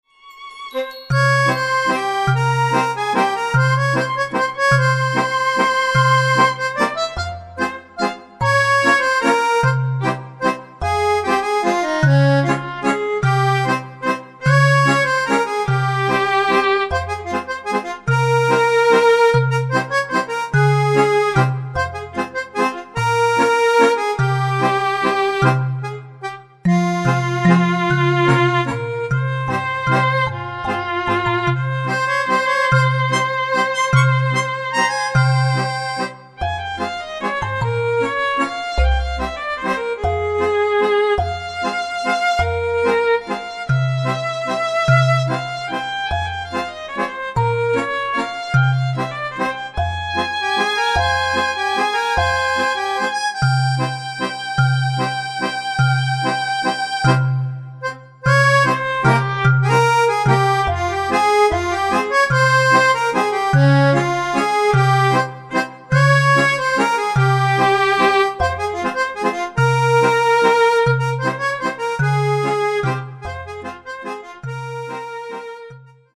Amelie-achtige filmmuziek, gebruikt in dansvoorstelling Pergola=Pistolet van LaMelis